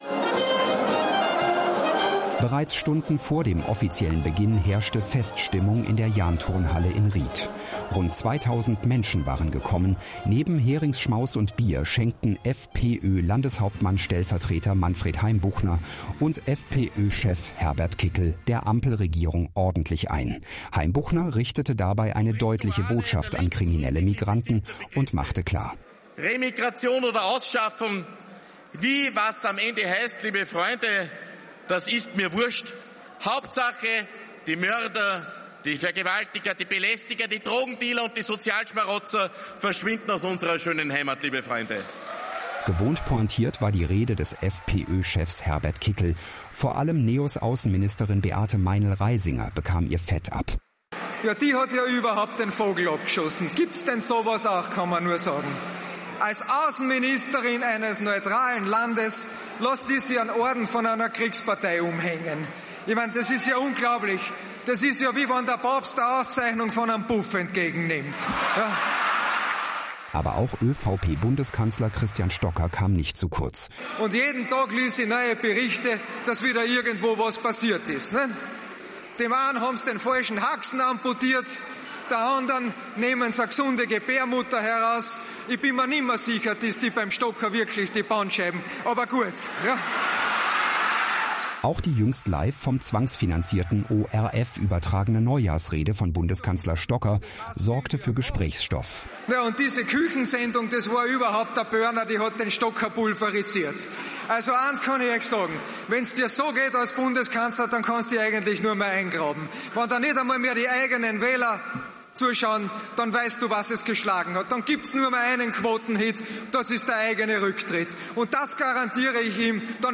In Ried in Oberösterreich versammelten sich rund 2.000 Anhänger der